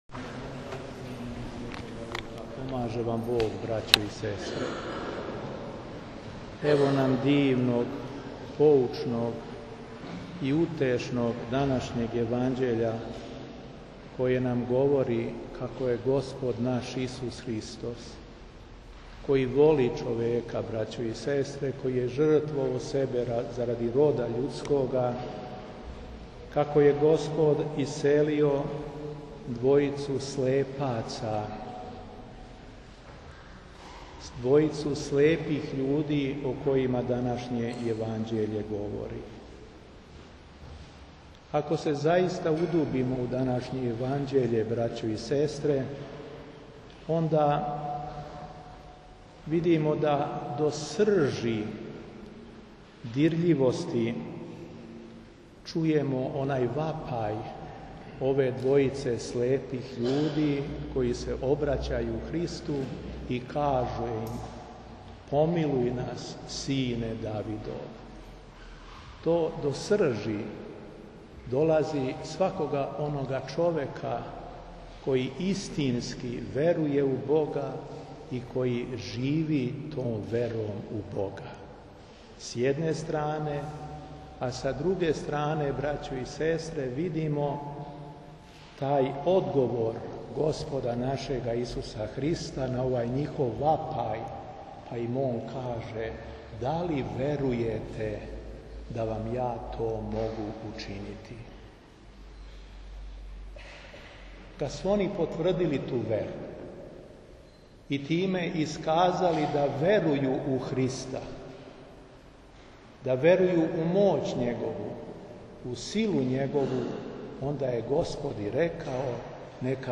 ЕВХАРИСТИЈСКО САБРАЊЕ У САБОРНОМ ХРАМУ НА ДАН УСПЕЊА СВЕТЕ АНЕ - Епархија Шумадијска
Беседа Епископа шумадијског Г. Јована